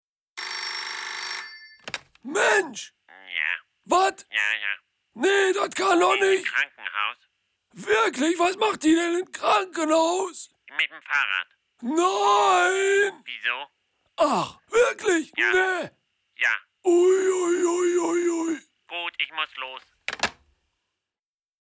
dunkel, sonor, souverän
Mittel plus (35-65)
Audio Drama (Hörspiel), Comedy, Imitation, Trick, Scene
Ruhrgebiet